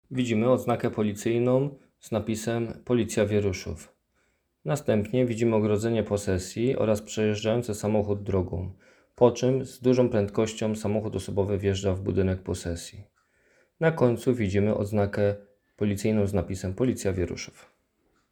Nagranie audio Galewice_audiodeskrypcja.m4a